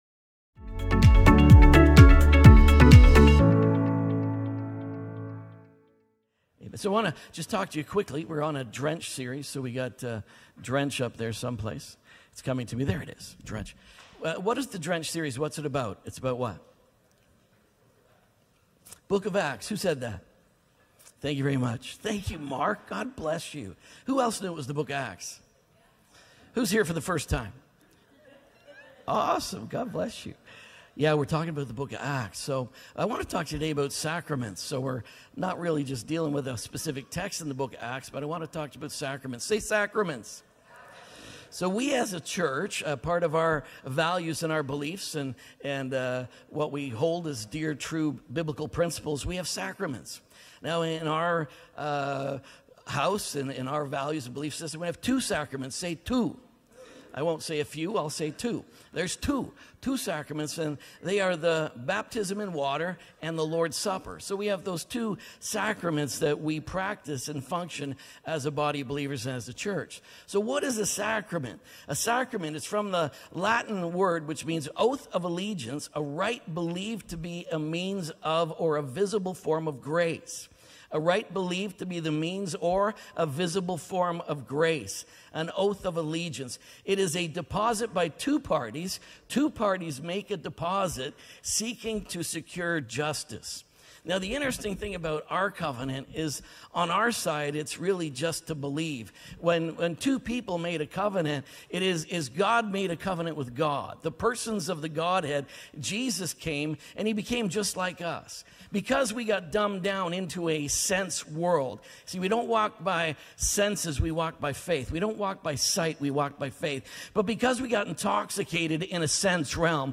SACREMENTS | DRENCH SERIES | SERMON ONLY.mp3